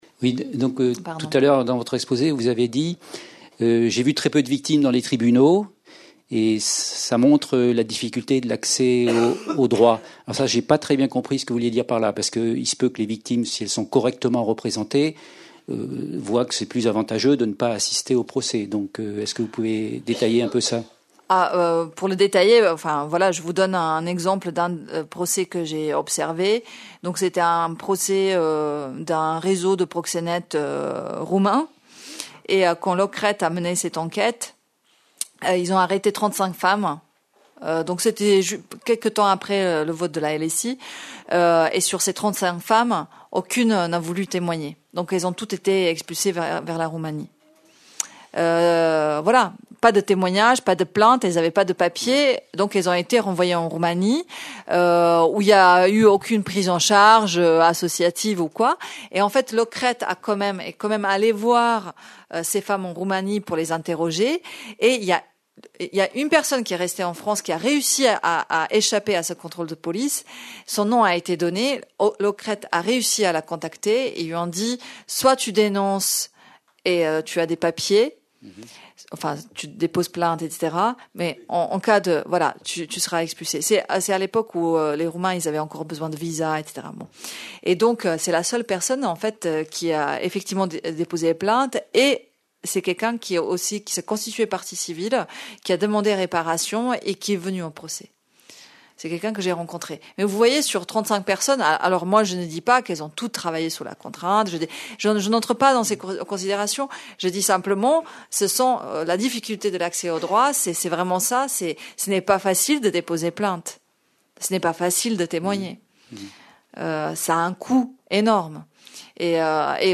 L'objectif de cette conférence est d'interroger la loi au regard des réalités vécues par les femmes étrangères en situation de prostitution.